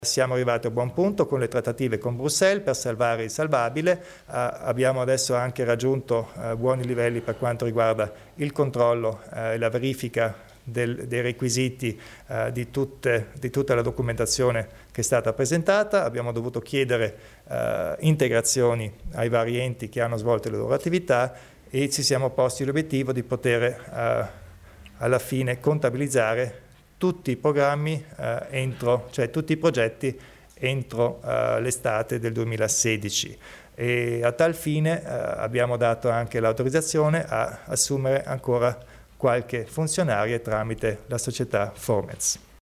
Il Presidente Kompatscher spiega la futura attività del Fondo Sociale Europeo